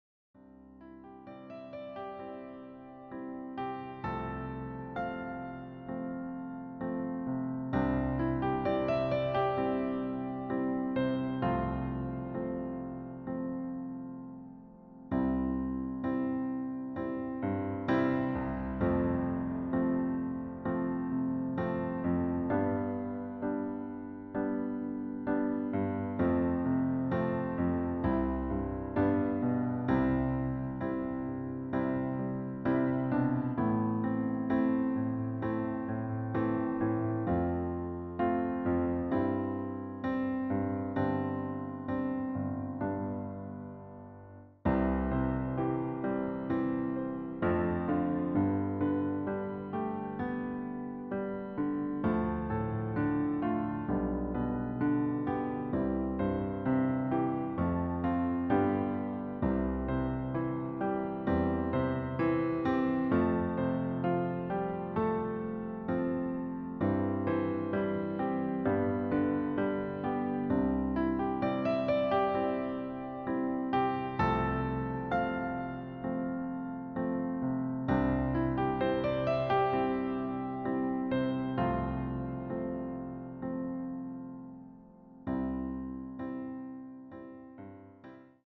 Klavier / Streicher